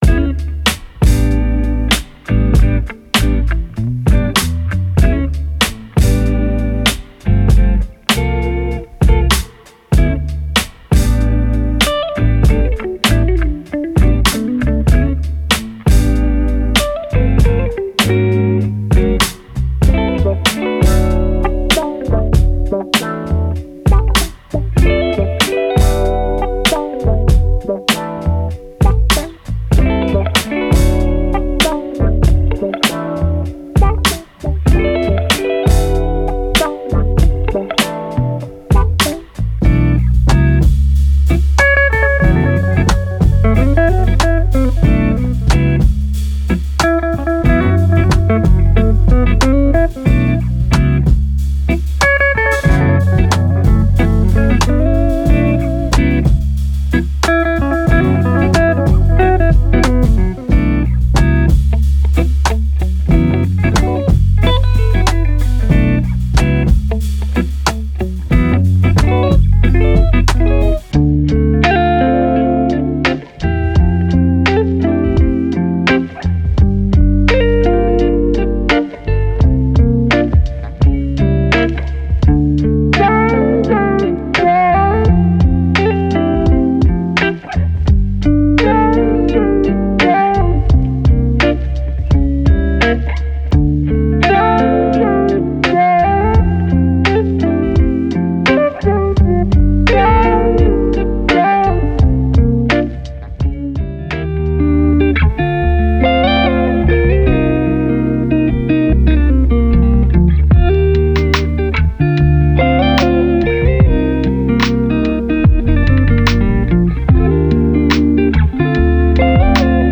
Genre:Neo Soul
なお、ドラムはデモ用途のみであり、本パックには含まれていません。
16 Chord Guitar Loops
10 Bass Guitar Loops
3 Wah Guitar Loops
3 Muted Guitar Loops